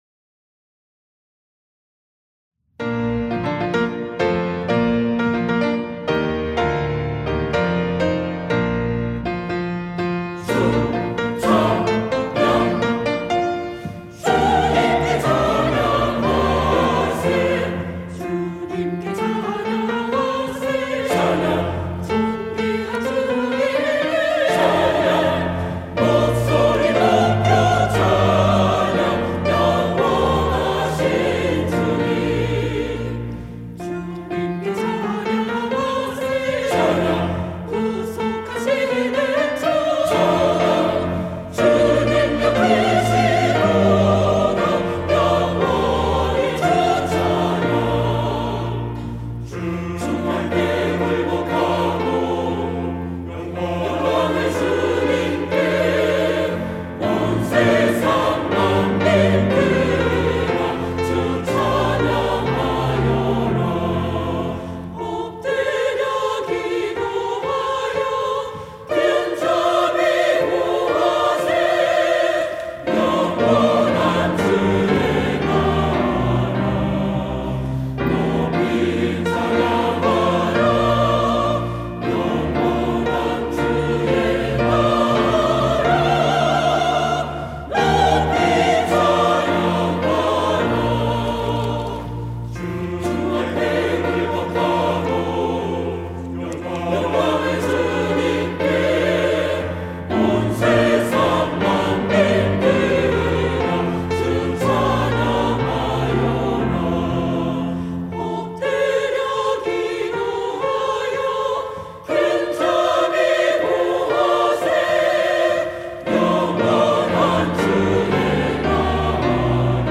시온(주일1부) - 주님께 찬양하세
찬양대